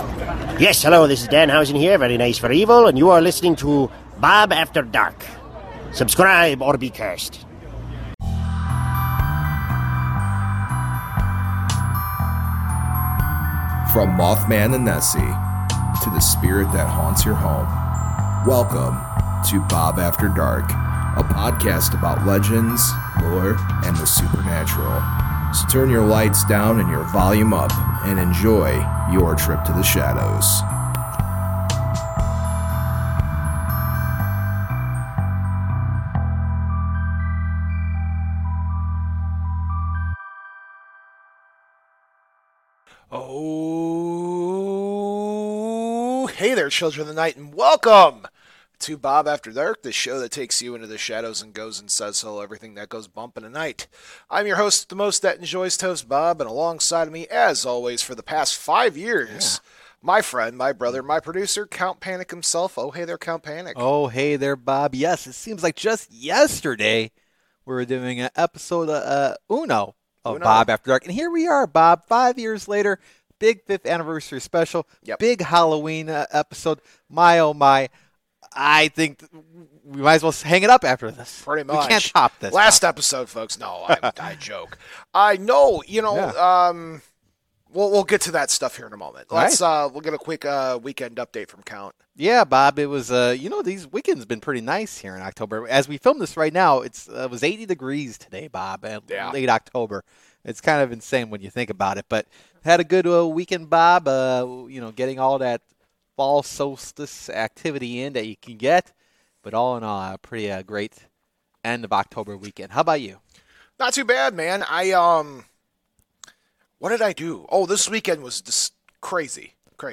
Well, it was indeed our anniversary, and we had a bunch of callers calling in with their spooky tales, and encounters they could not explain. There's also some news, and a brief history of Halloween.